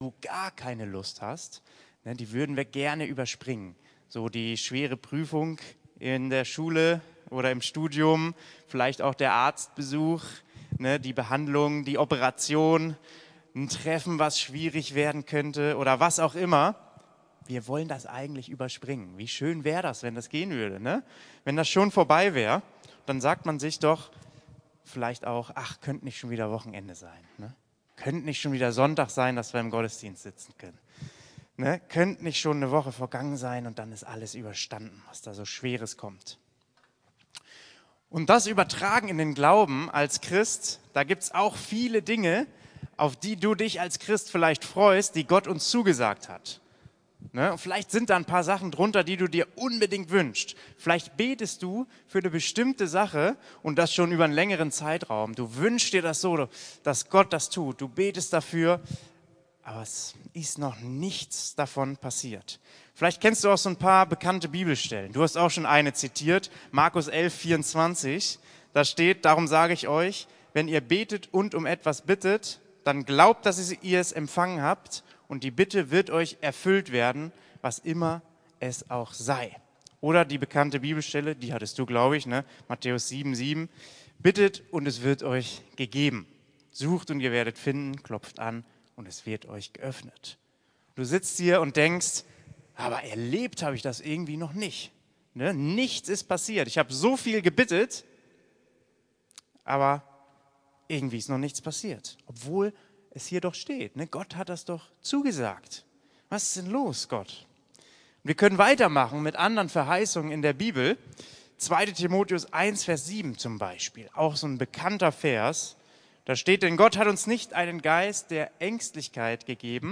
Predigten FeG Schwerin Podcast